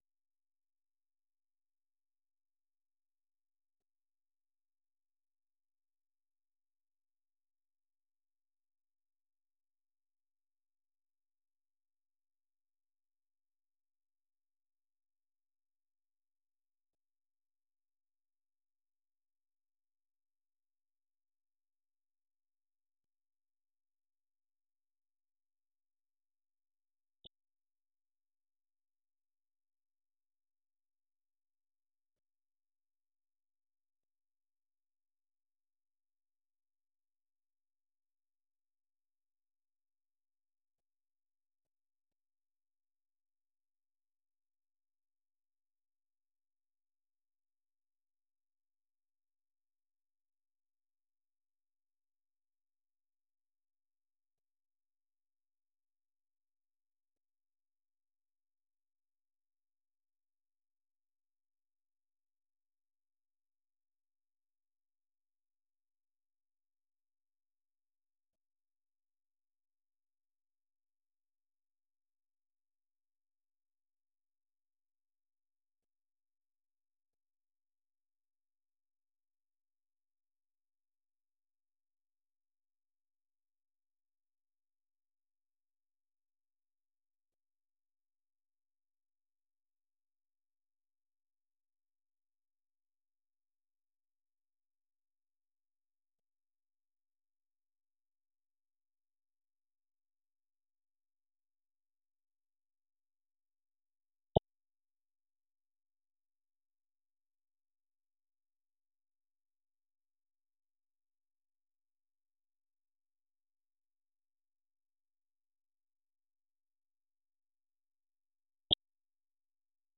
ข่าวประจำวัน